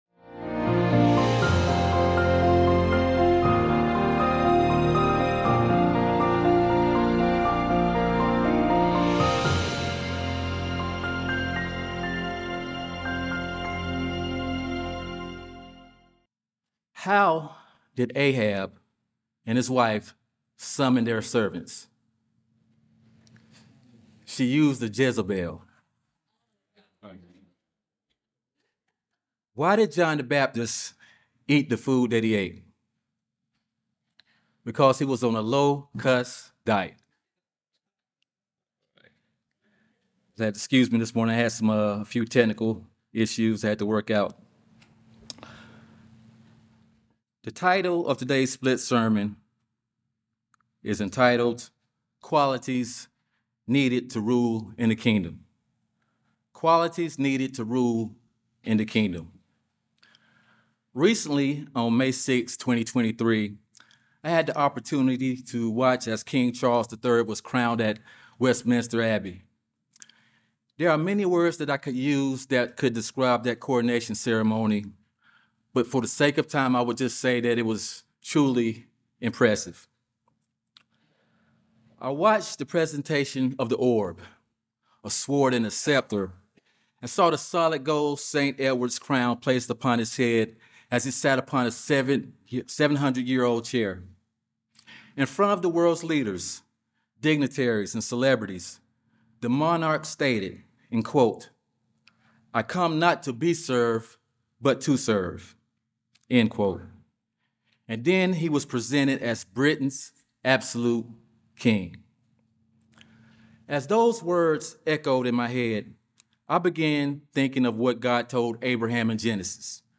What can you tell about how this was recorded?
Given in Charlotte, NC Columbia, SC Hickory, NC